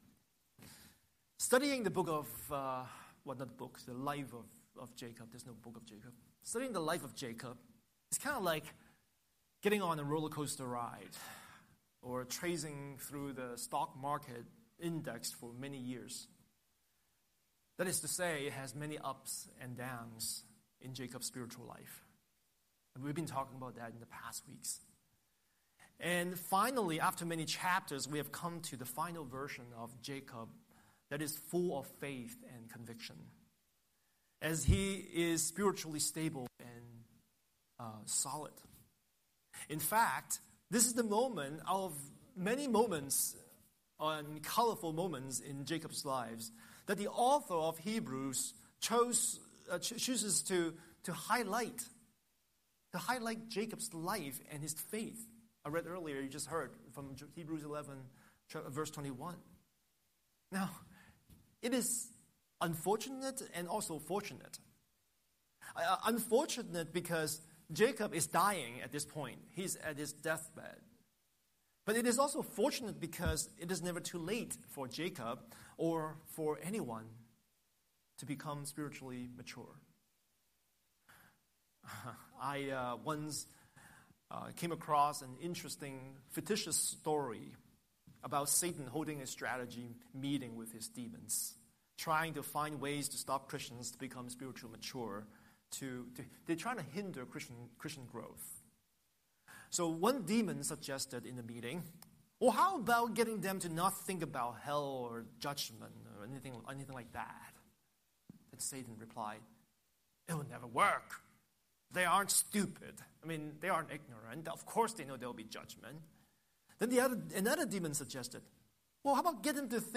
Scripture: Genesis 45:25–47:31 Series: Sunday Sermon